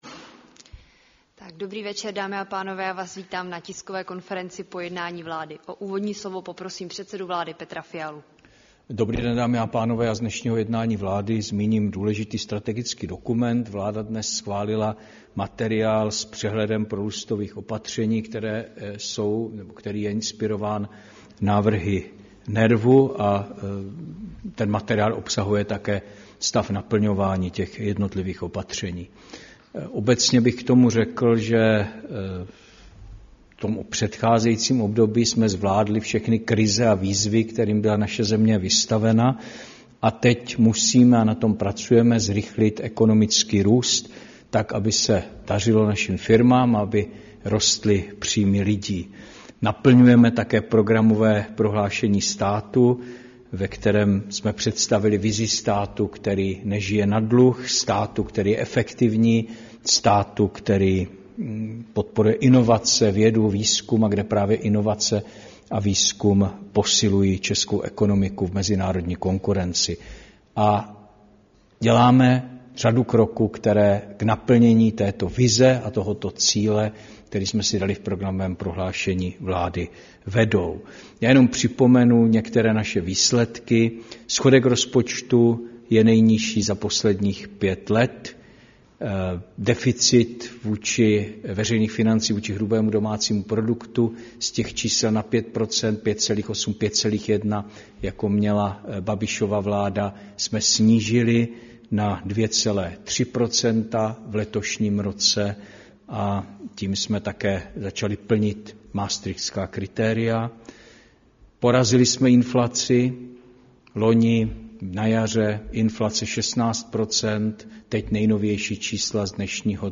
Tisková konference po jednání vlády, 10. července 2024